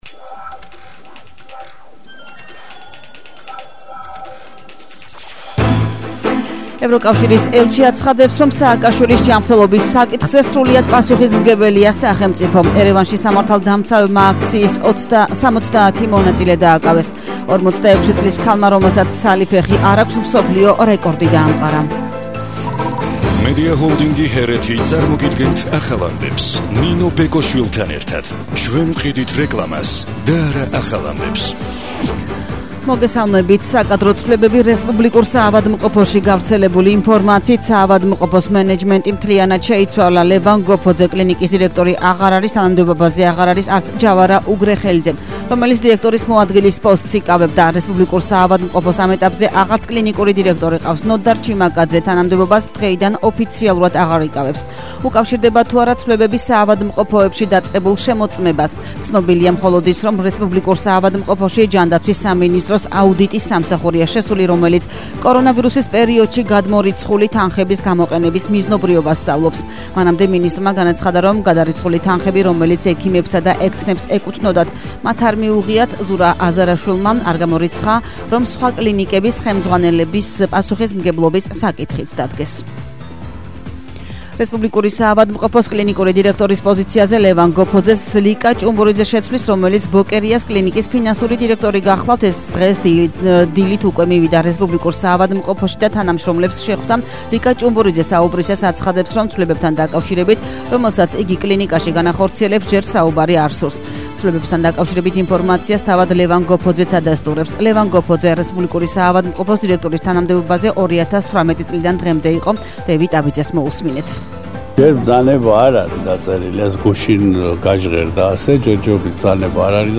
ახალი ამბები